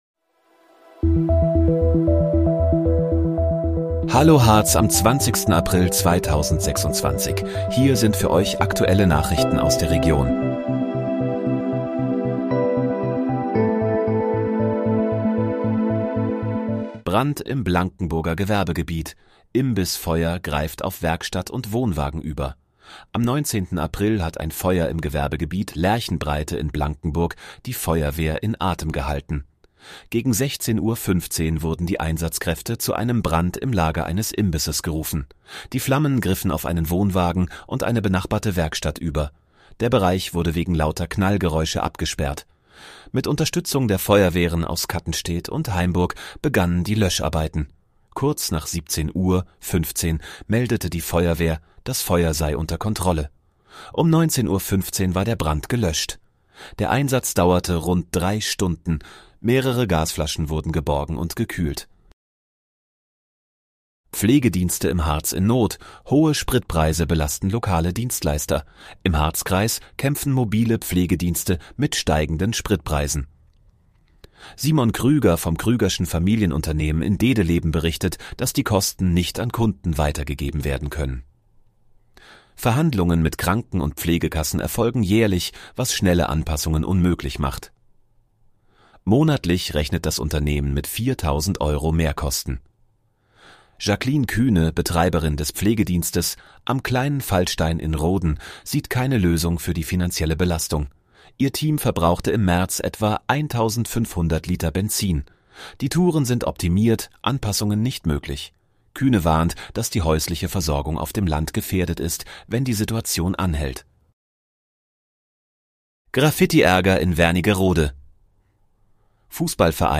Hallo, Harz: Aktuelle Nachrichten vom 20.04.2026, erstellt mit KI-Unterstützung